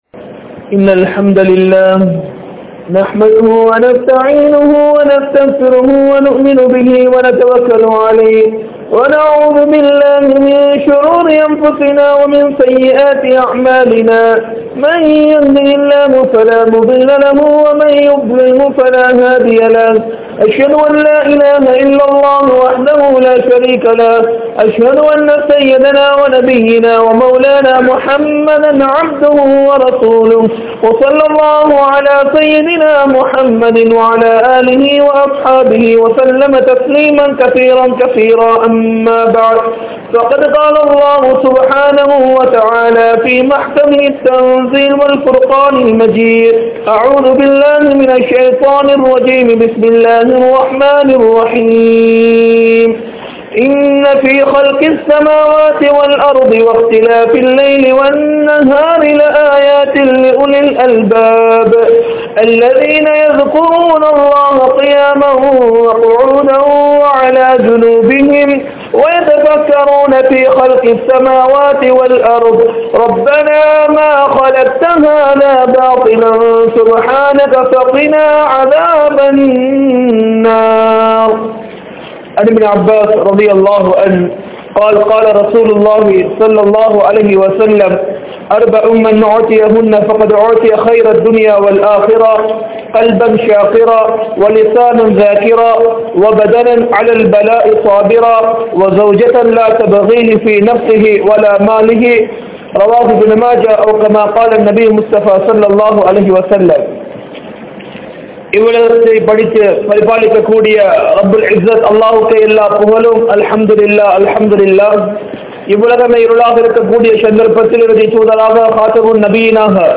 Nantri Ulla Adiyaan Yaar? (நன்றி உள்ள அடியான் யார்?) | Audio Bayans | All Ceylon Muslim Youth Community | Addalaichenai
Kandy, Aruppola, Sayf Jumua Masjidh